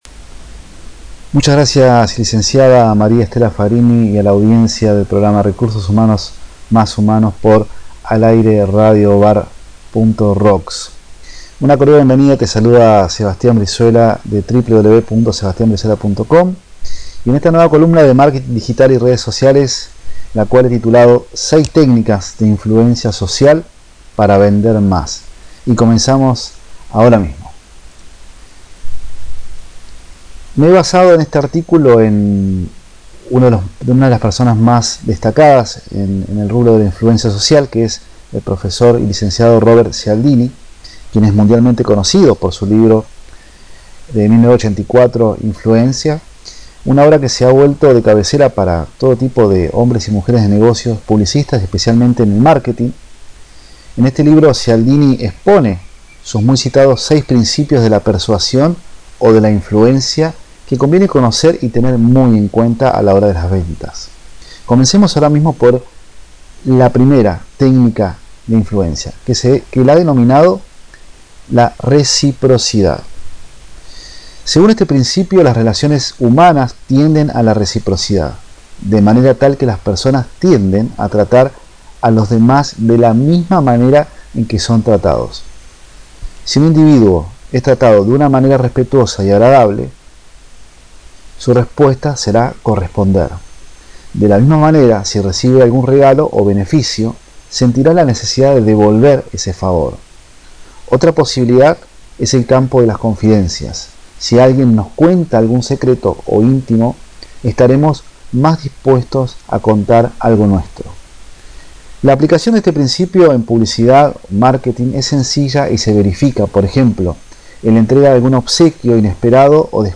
Si estas buscando información de Como Aumentar Tu Ventas, debes escuchar la grabación de la columna radial que comparto en el audio de abajo, donde te comento sobre las 6 Técnicas para Vender Mas sobre Influencia Social que afectan de manera inconsciente en la toma de decisiones de tus Potenciales Clientes.